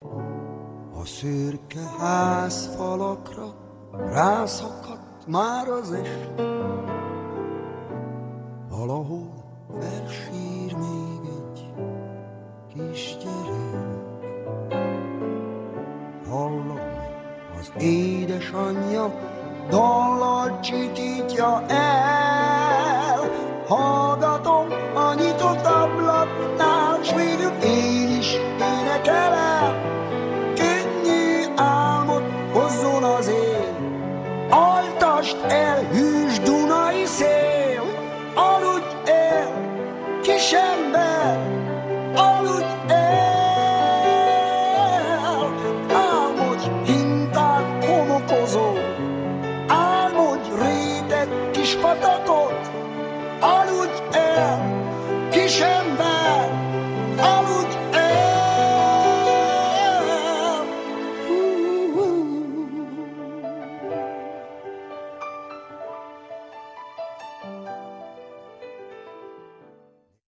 Lattmann Béla: Bass